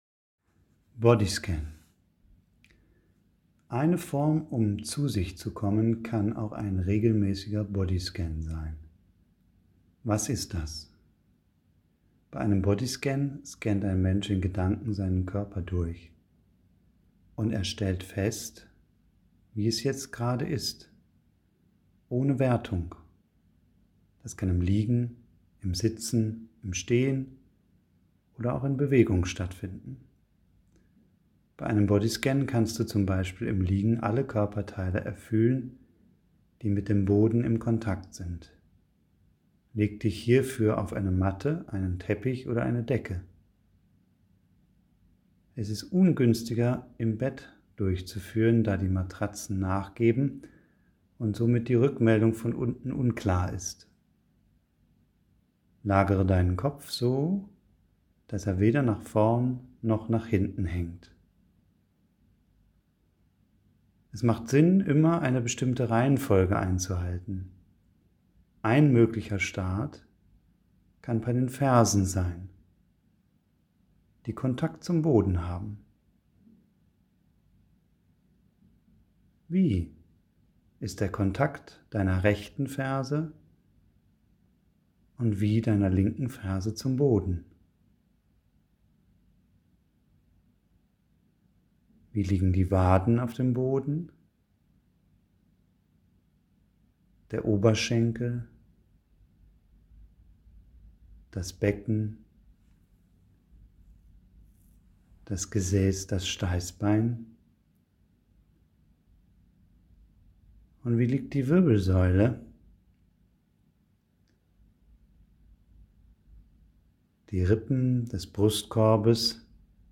Bodyscan Audio 1
Hier habe ich dir einen möglichen Bodyscan in einer Audiodatei aufgenommen.
Er ist so angeleitet,
Bodyscan 8 Min Boden liegend.mp3